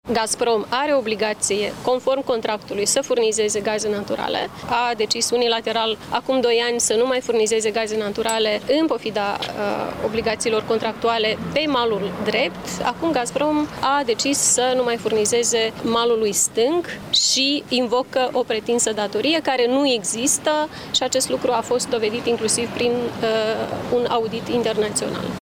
Maia Sandu: „Acum, Gazprom a decis să nu mai furnizeze malului stâng și invocă o posibilă datorie care nu există”